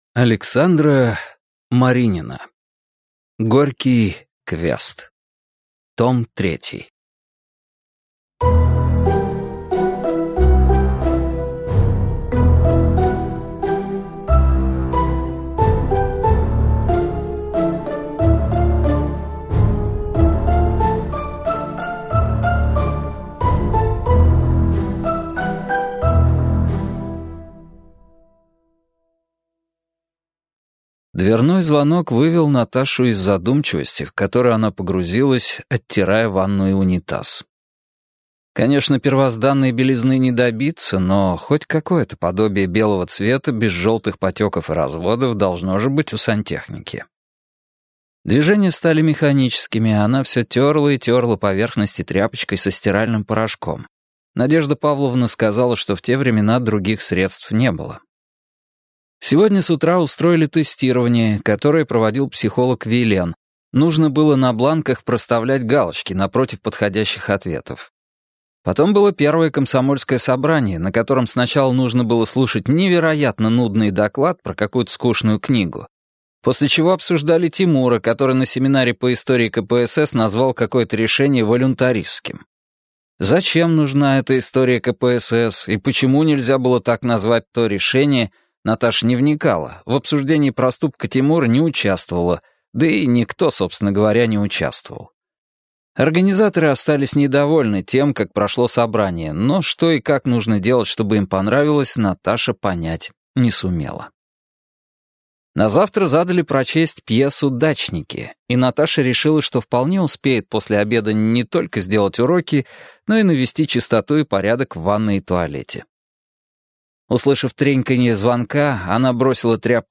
Аудиокнига Горький квест. Том 3 | Библиотека аудиокниг
Прослушать и бесплатно скачать фрагмент аудиокниги